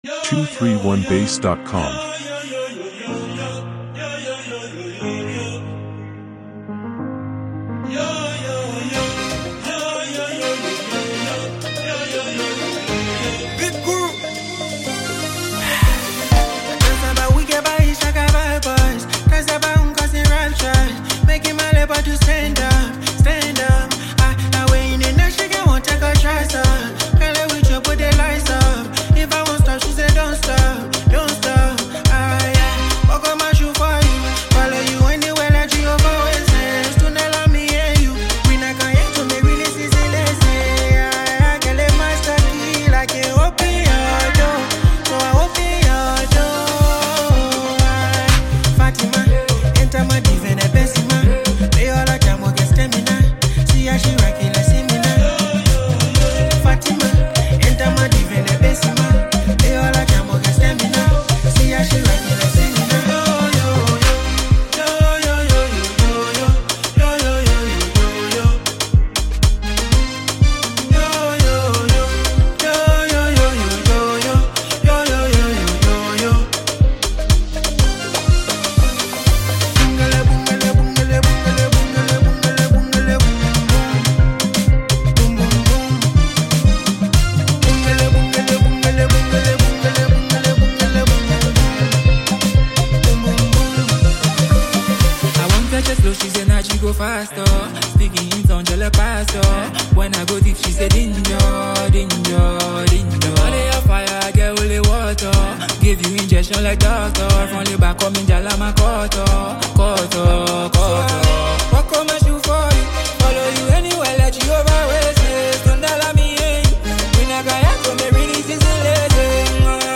club banger
This Afro-beat track is already generating buzz.
smooth flow
vocals